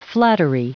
Prononciation du mot flattery en anglais (fichier audio)
Prononciation du mot : flattery